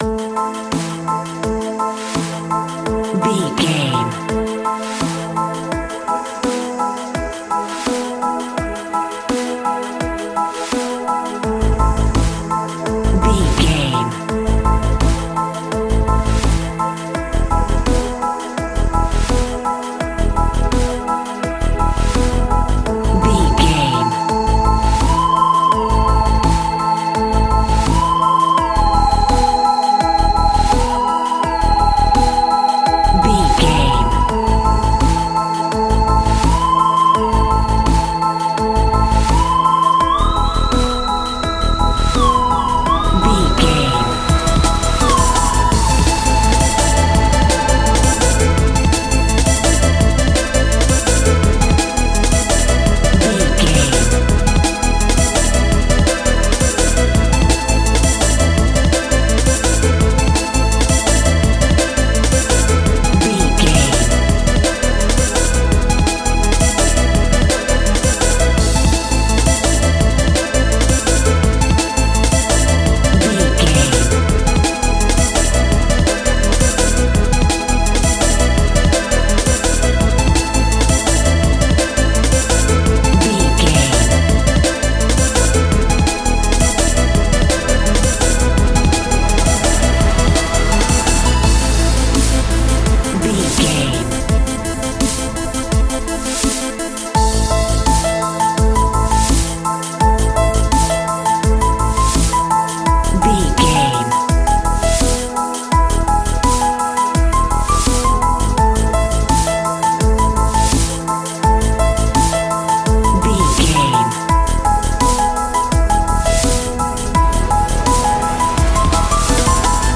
Middle East Drum and Bass.
Aeolian/Minor
Fast
hypnotic
industrial
frantic
aggressive
dark
drum machine
synthesiser
piano
electronic
sub bass
synth leads